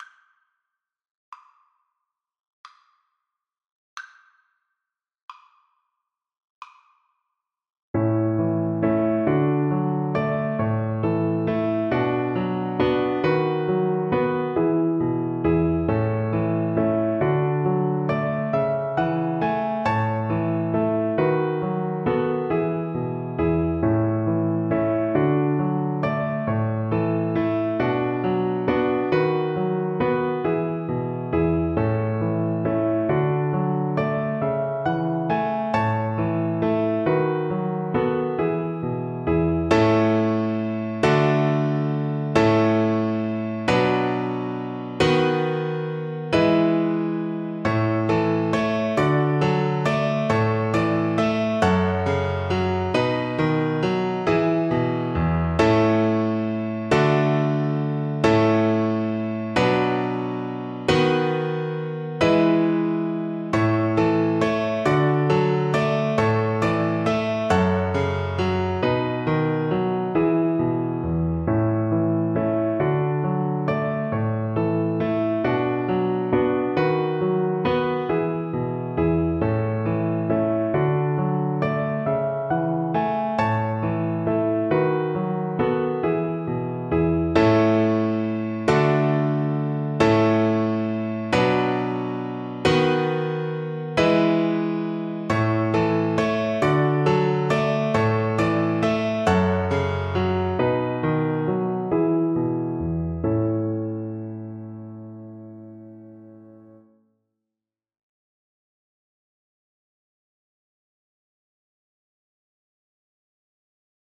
Violin
A major (Sounding Pitch) (View more A major Music for Violin )
9/8 (View more 9/8 Music)
Easy Level: Recommended for Beginners with some playing experience
Traditional (View more Traditional Violin Music)